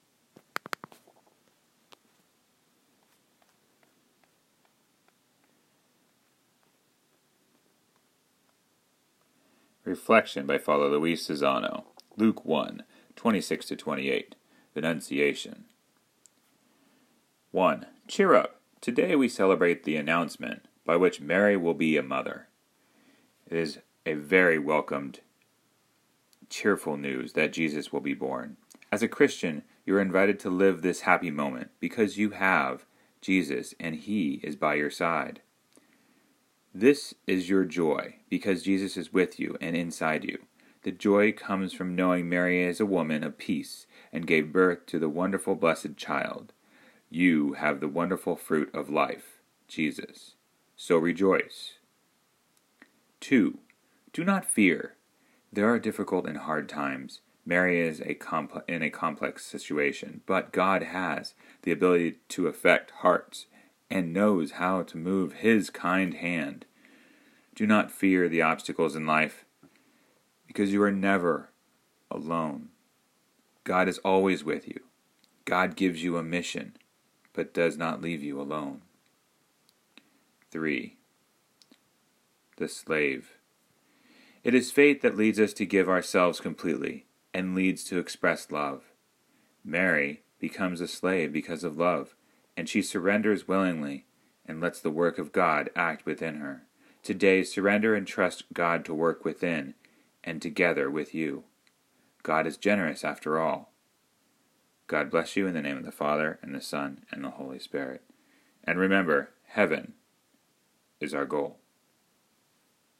Daily Meditation